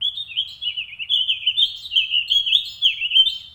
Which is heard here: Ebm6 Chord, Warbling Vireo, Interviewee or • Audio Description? Warbling Vireo